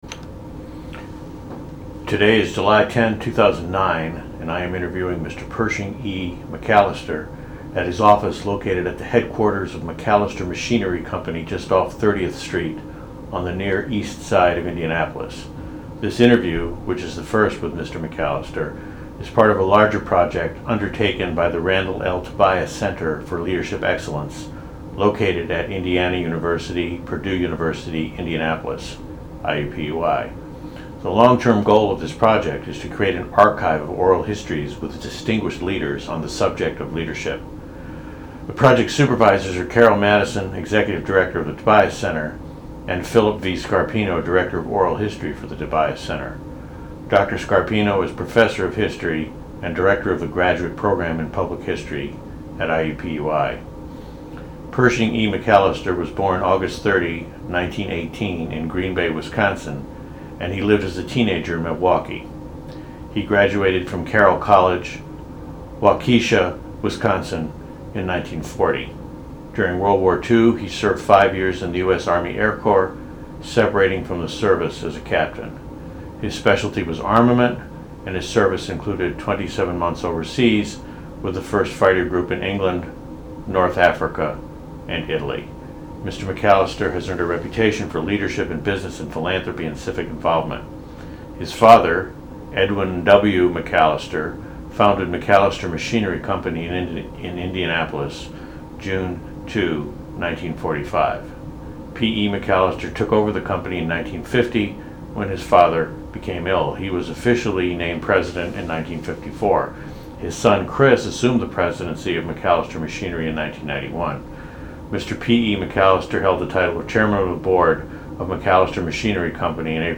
Oral History Project